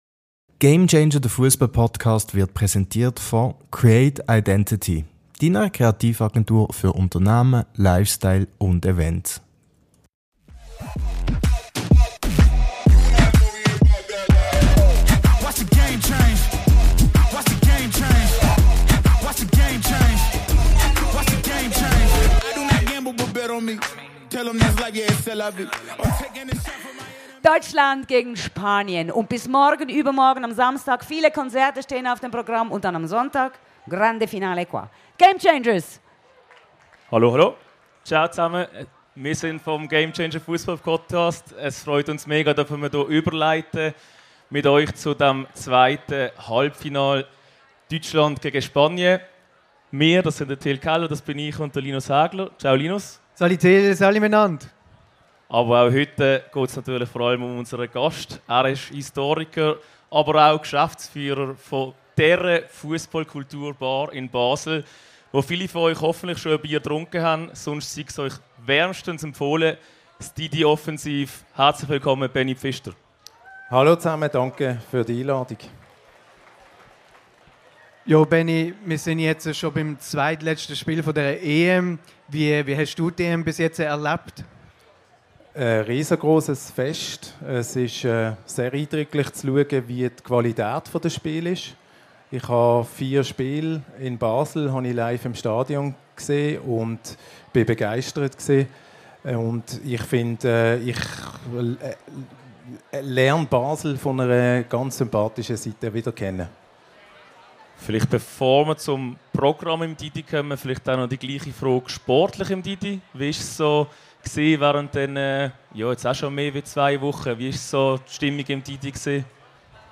Erneut kurz und knackig, als Teil des Liveprogramms auf dem Barfi.